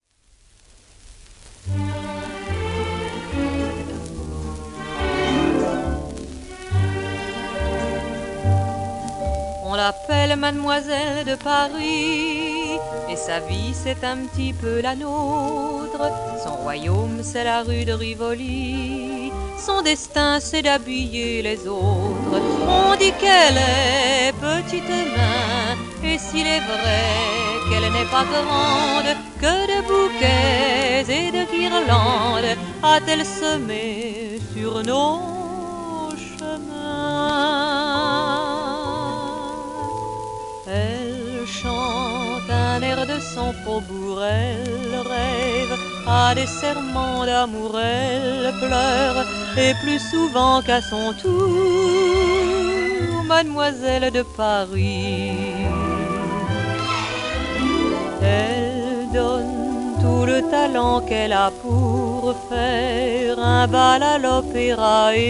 w/オーケストラ